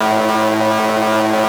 lazer2.wav